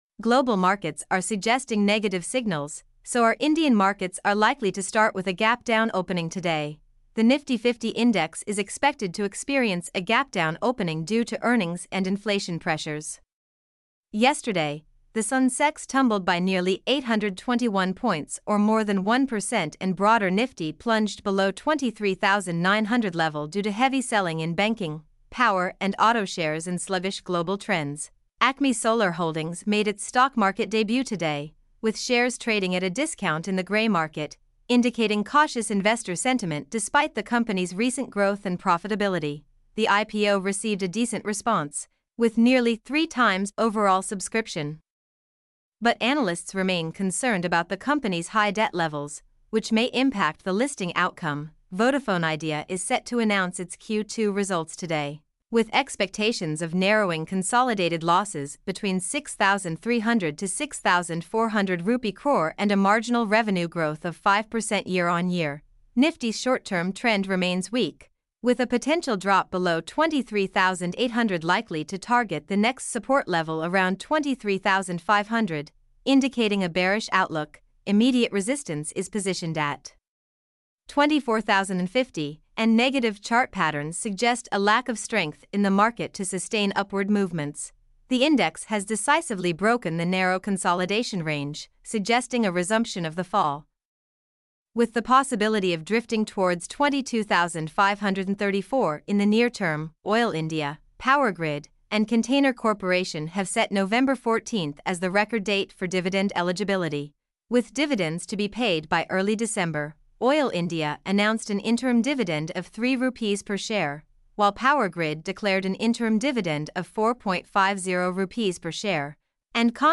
mp3-output-ttsfreedotcom-28.mp3